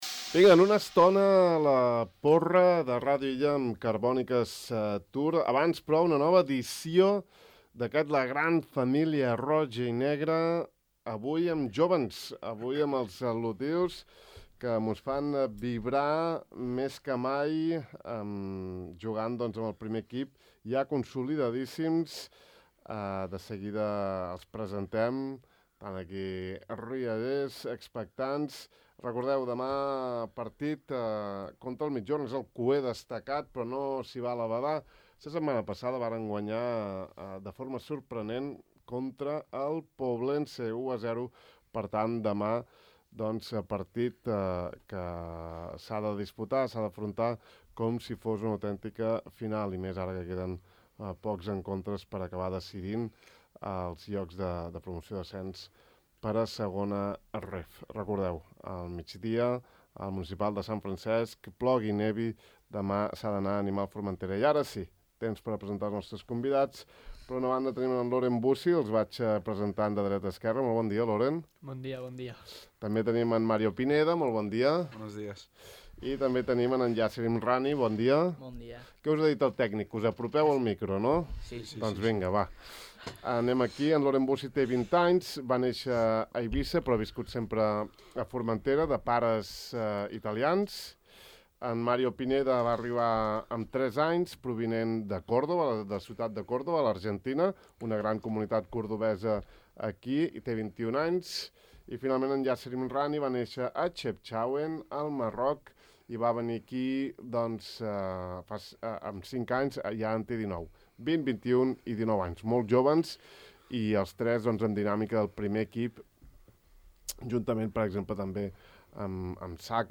‘La gran família roig-i-negre’ és un espai de De far a far en què a través d’entrevistes disteses coneixem alguns dels protagonistes de la SD Formentera, des dels jugadors del primer equip, equip tècnic, entrenadors de l’escola de futbol i altres integrants del club.